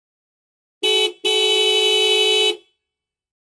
VEHICLES - HORNS 1999 TOYOTA CAMRY: Car horn, 2 toots, Toyota Camry.